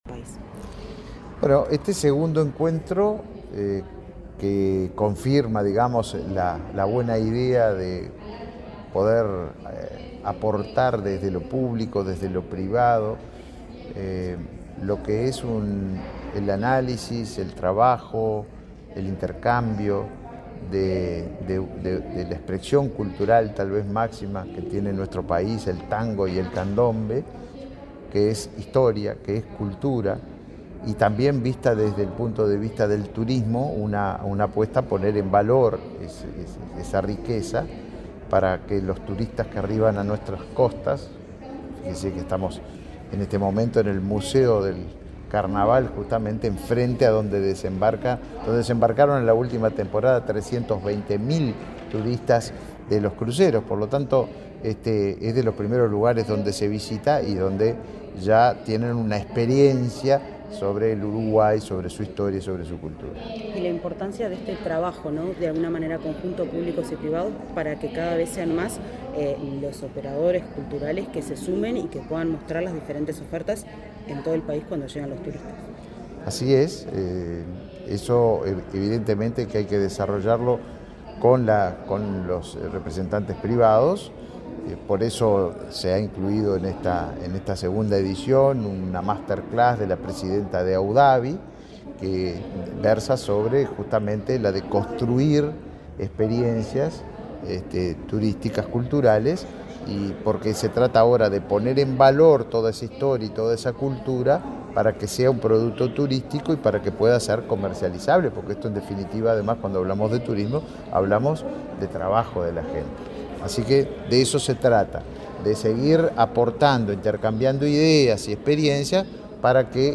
Declaraciones del ministro de Turismo, Tabaré Viera
El ministro de Turismo, Tabaré Viera, dialogó con la prensa, luego de participar este miércoles 30 en el Museo del Carnaval, de la apertura del